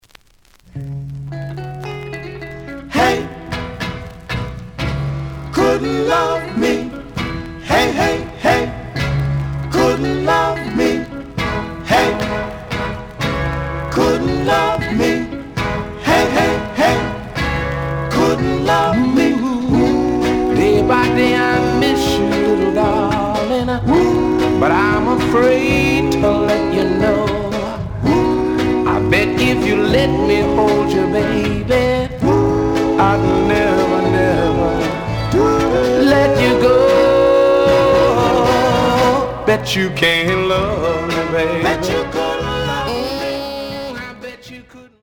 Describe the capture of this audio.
The audio sample is recorded from the actual item. Slight edge warp. But doesn't affect playing. Plays good.)